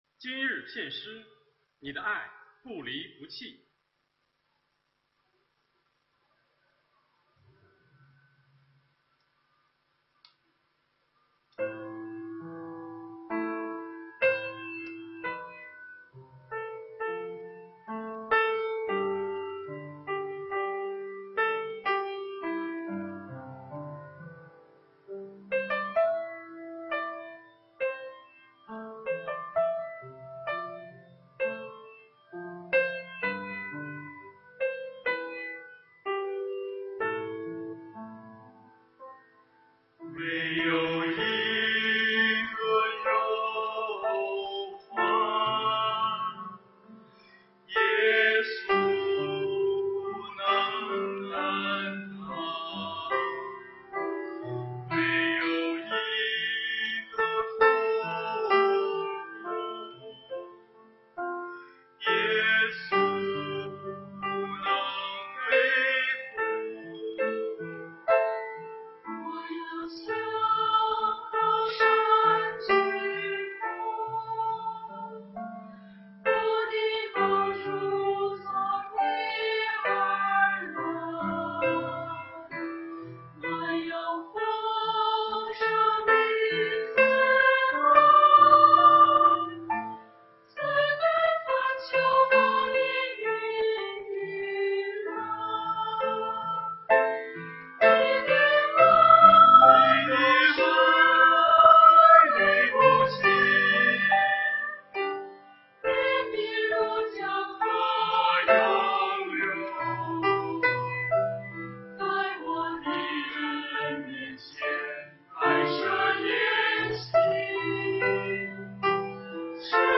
团契名称: 青年诗班 新闻分类: 诗班献诗